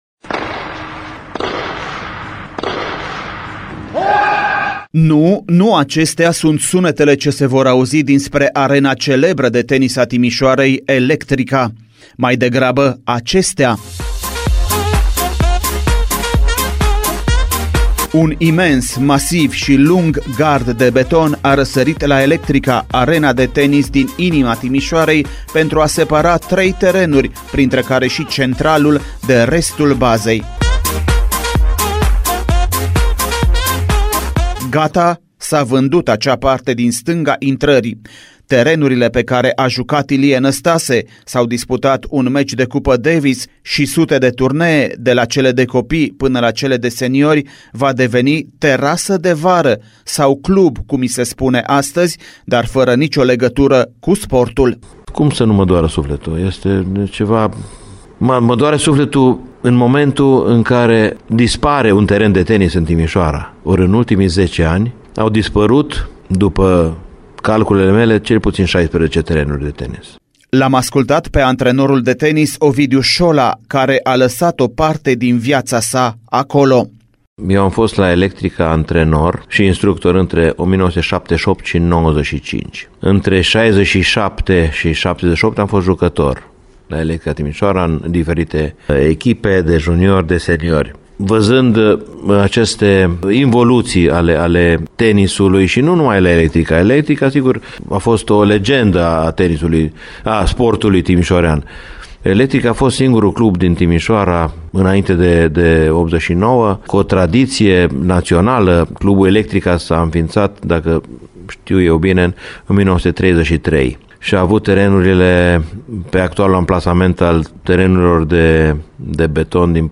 Un reportaj AUDIO despre declinul Electricii